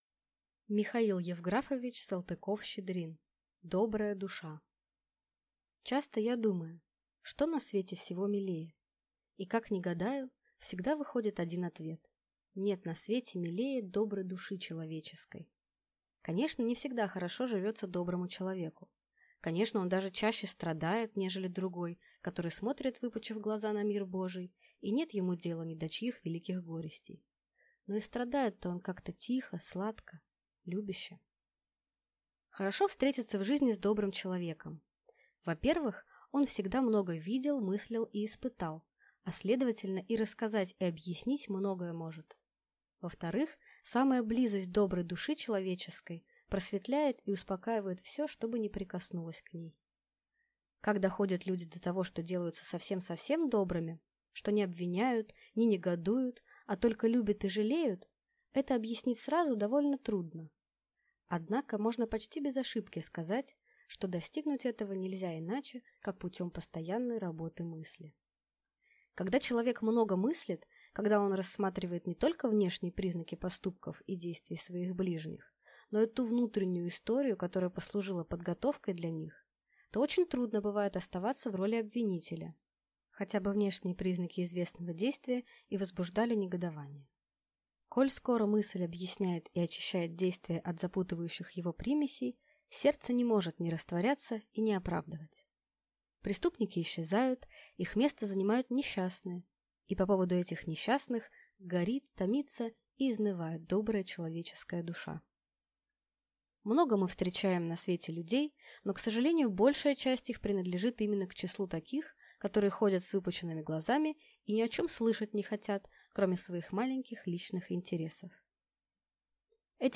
Аудиокнига Добрая душа | Библиотека аудиокниг